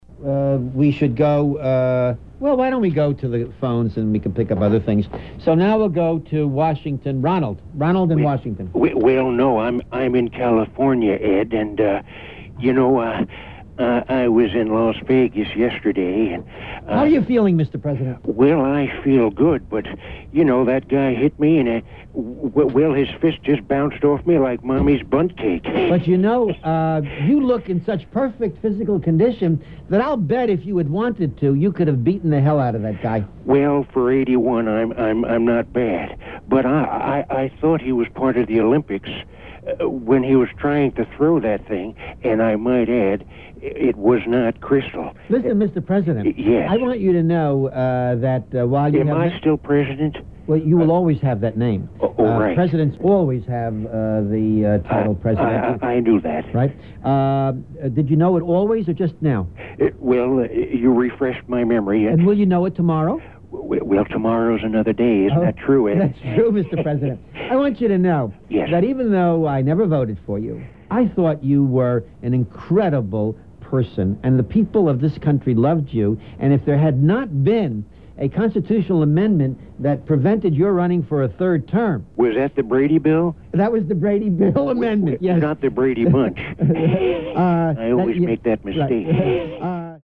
celebrity imitations
Mayor Ed Koch Radio Show Call-In